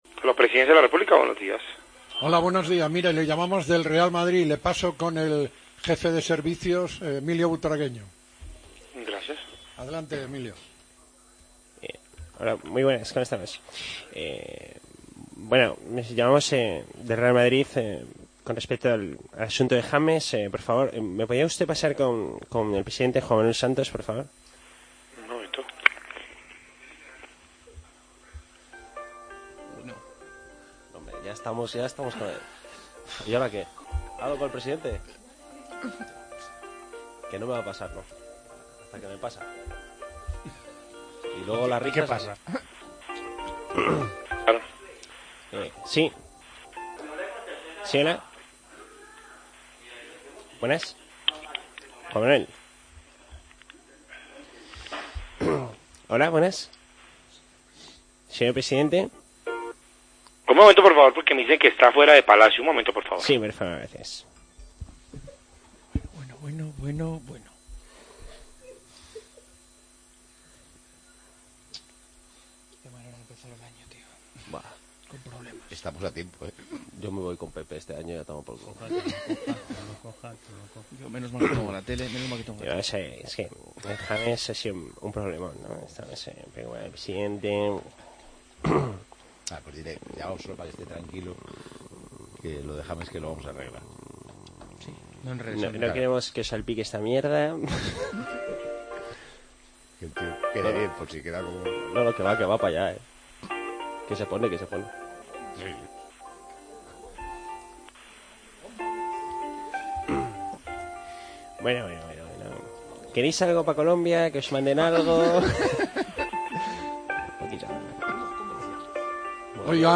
habla con el presidente de Colombia sobre el incidente con James del día de Año Nuevo, en el que la Policía pilló al jugador colombiano conduciendo a 200km/h.